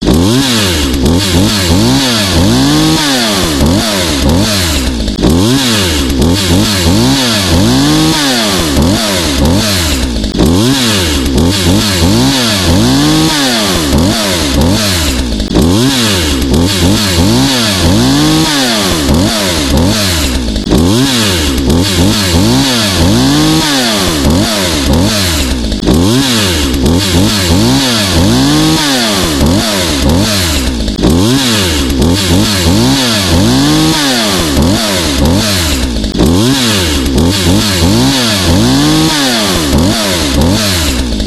Kettensäge Klingelton
Einfach herunterladen und den wilden Sound der Motorsäge jederzeit genießen!
kettensaege-klingelton-de-www_tiengdong_com.mp3